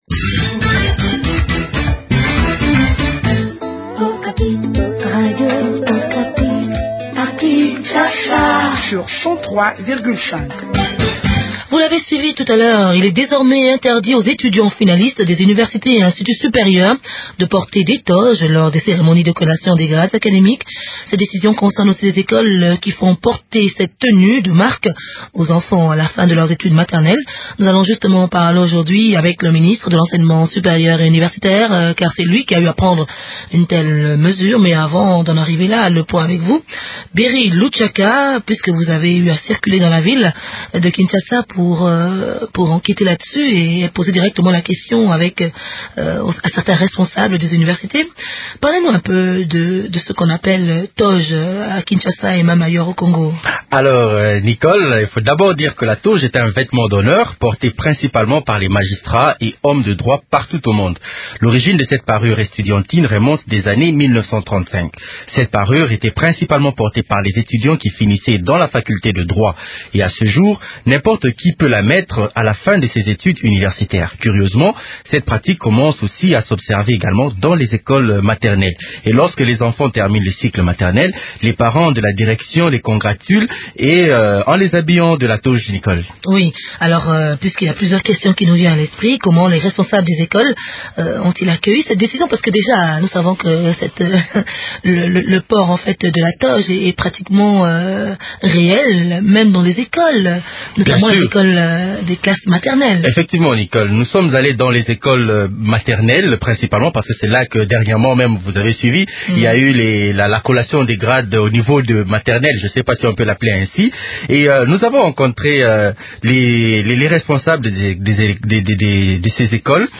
Léonard Masuga Rugamiga, ministre de l’Enseignement Supérieur et Universitaire est l’invité